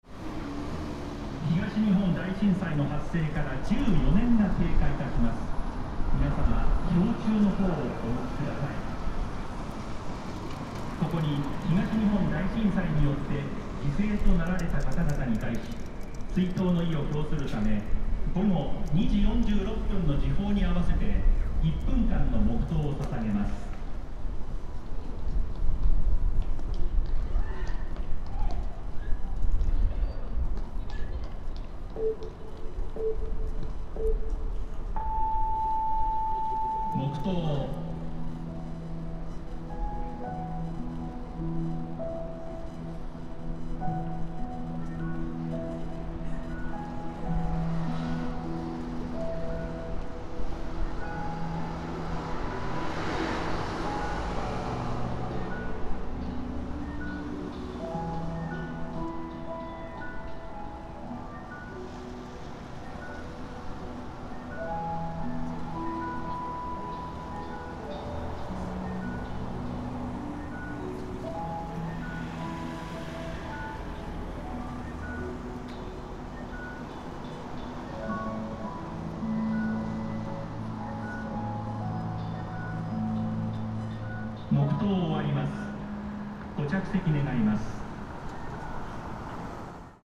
I was at the Machi-naka Square at 14:46, just after 13 years from the quake. ♦ Many people gathered in the square and prayed silently. ♦ The construction works continued during the time of the silent prayer.